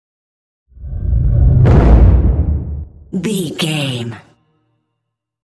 Dramatic deep whoosh to hit trailer
Sound Effects
Atonal
dark
intense
tension
woosh to hit